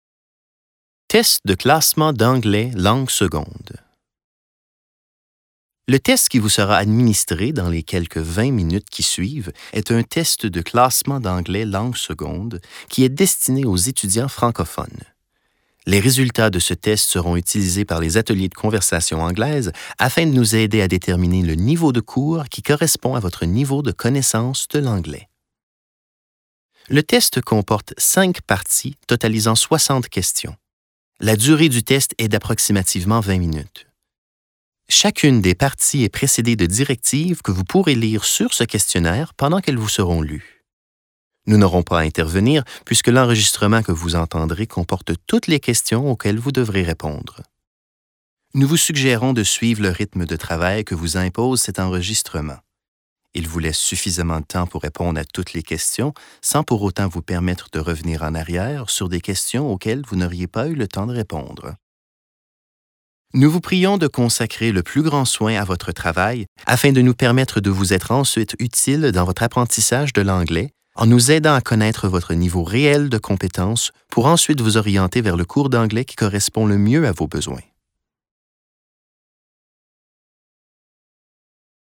Each section begins with a series of written instructions that are read aloud to you.